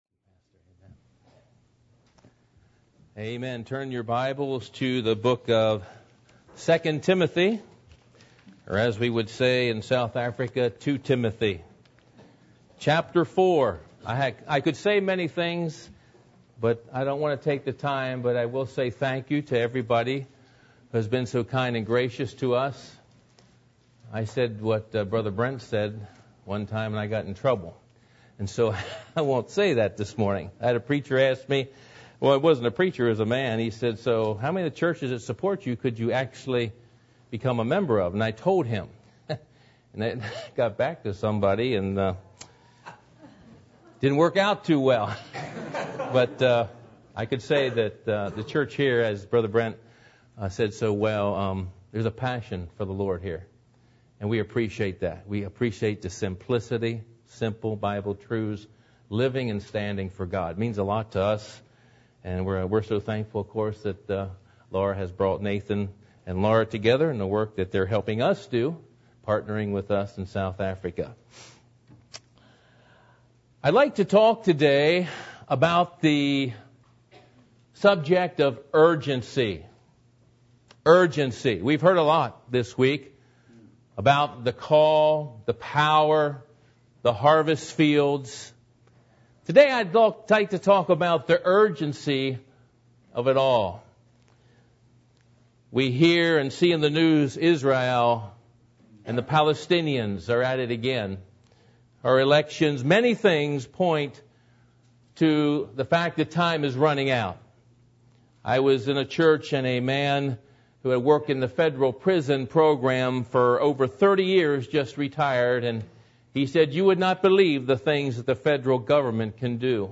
Missions Conference 2012 Service Type: Sunday Morning %todo_render% « Daniel